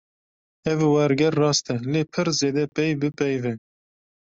/wɛɾˈɡɛɾ/